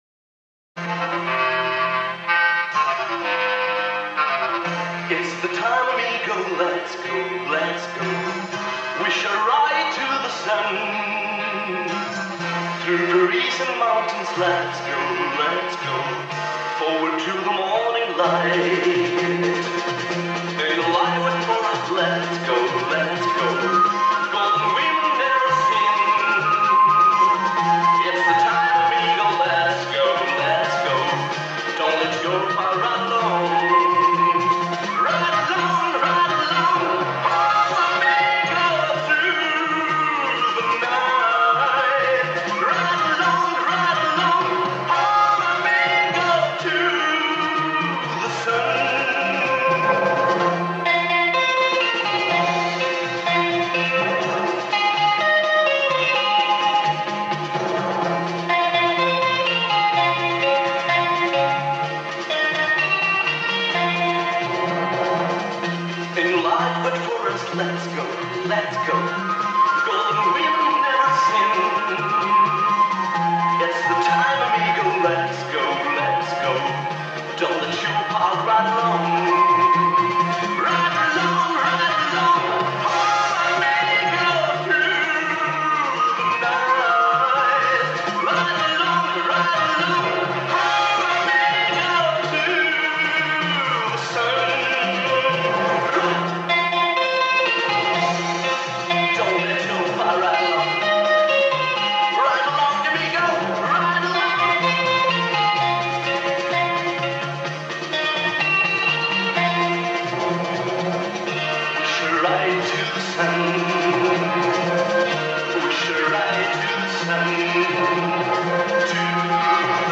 ※Mp3なので音質落ちてます。
主題歌
※出来はNGなんだけど...。